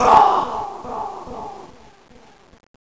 ■悲鳴
マイクで録音し、Wpaseで、ビットや、周波数を下げ、低容量にしました。
ソースは自分の声です。
エコーがかかっているのは、音師さんにやってもらいました。